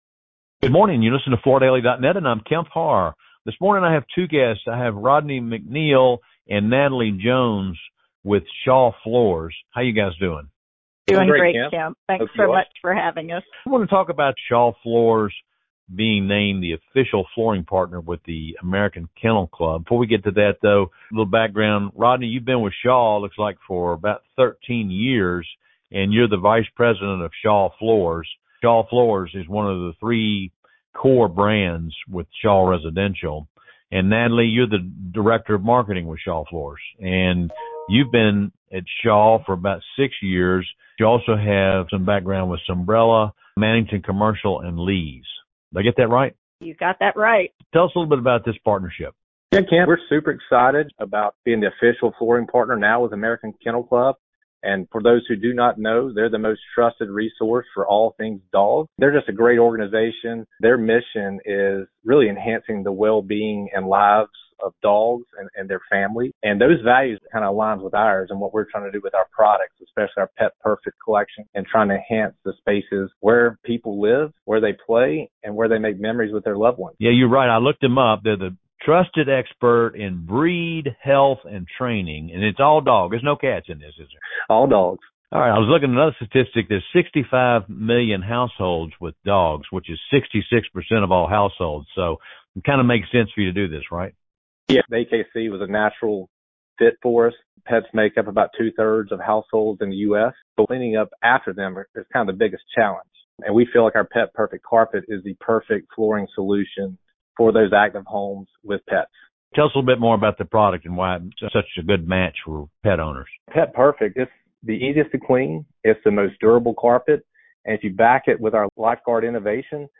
Listen to the interview for more details including the dealer tie-in designed to bring more pet owners into Shaw’s retailer’s stores.